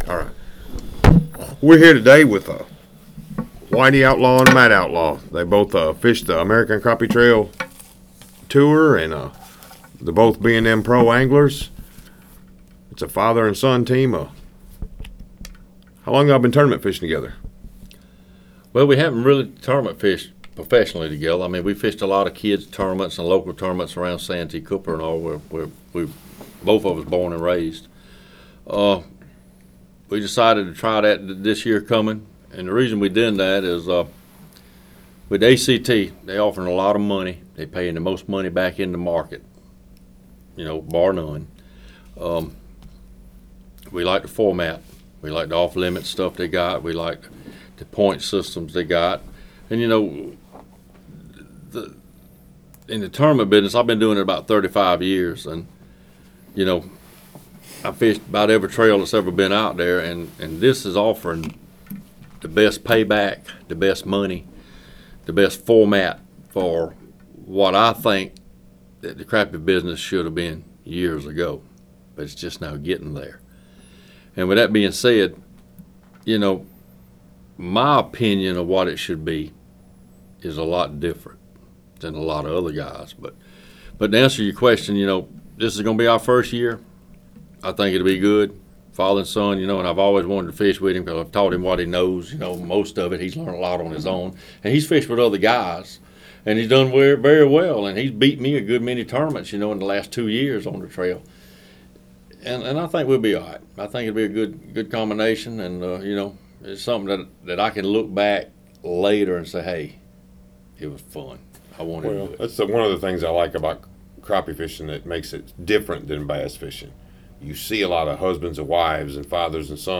A candid discussion